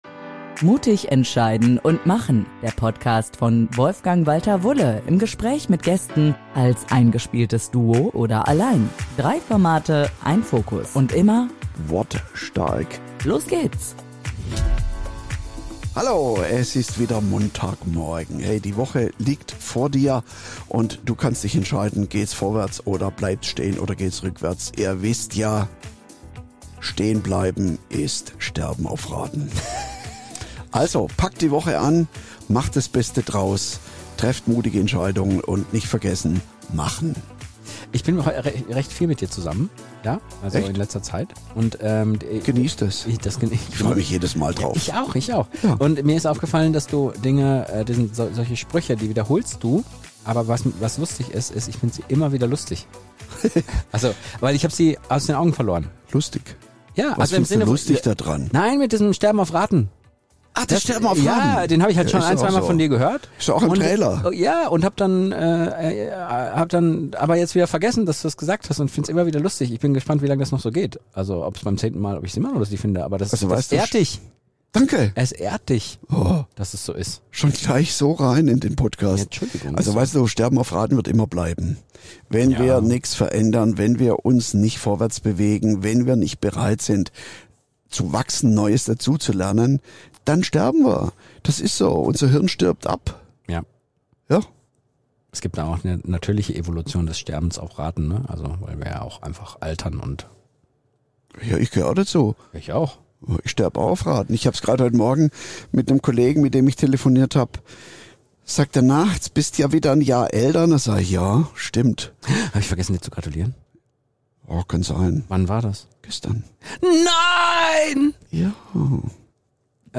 Eine Folge voller Klartext, Humor und Tiefgang – mit zwei sehr persönlichen unpopulären Meinungen zum Schluss, die garantiert Diskussionsstoff liefern.